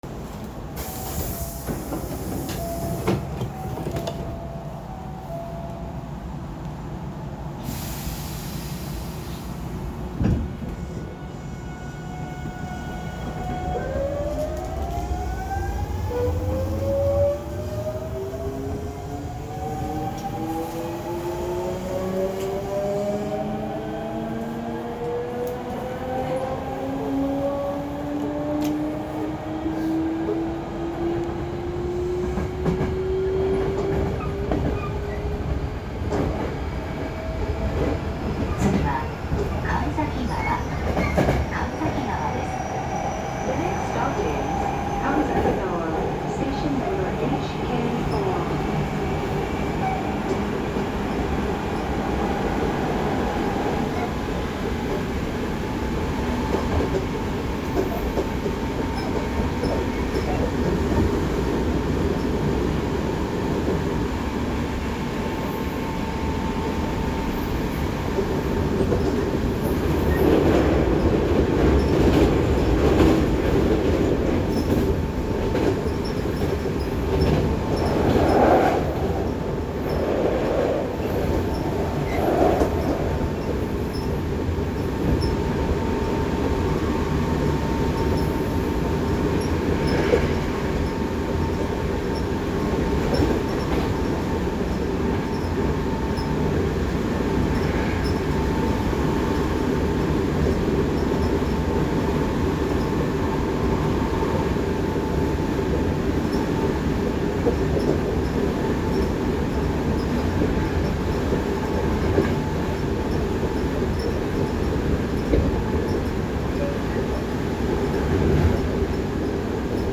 ・8000系走行音
【神戸本線】園田→神崎川
同系列の8300系の走行音は何通りかあるのですが、8000系は東芝GTOとなります。東芝のGTOとしては聞き慣れた音ですが、今となってはこれも貴重です。